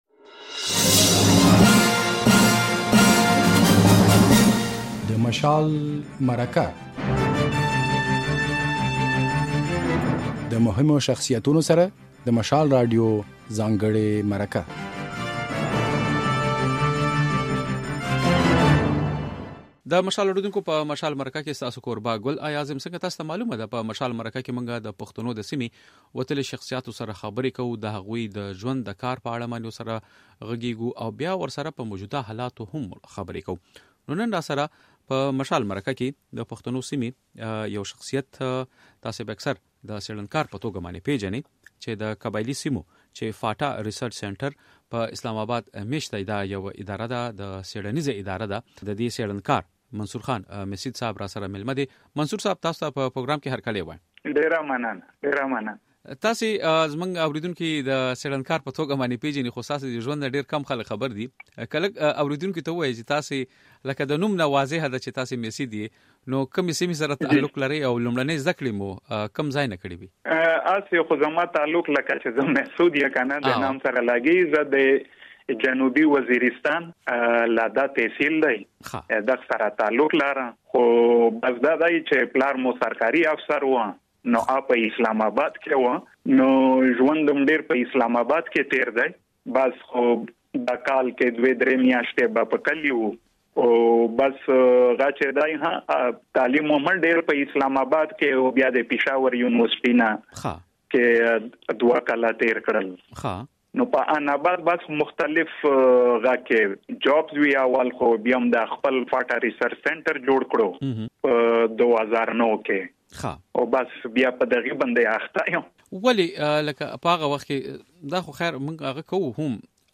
د نوموړي په وینا، په قبايلي ضلعو کې د ناامنۍ ترشا وجوهات يوه سابقه لري. هغه زیاته کړه چې پښتانه بايد د سياسي نعرو پرځای د خپلو ګټو او زیانونو په اړه فکر وکړي. بشپړه مرکه واورئ.